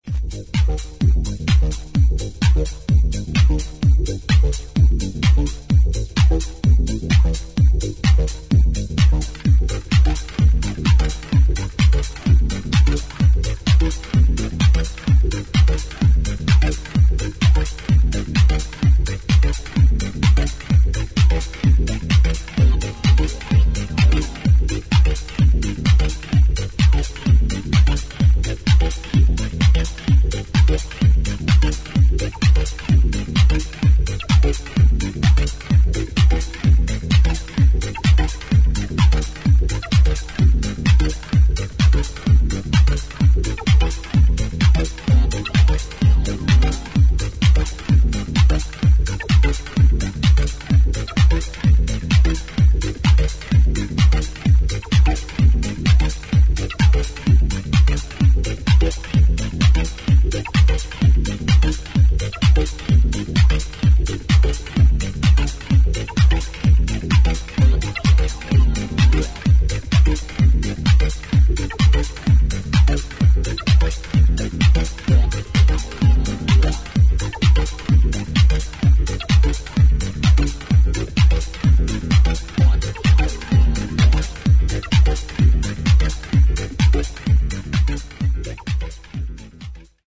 独特のマシン・ファンクネスを感じさせる